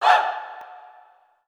Tm8_Chant56.WAV